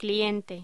Locución: Cliente
voz cliente sonido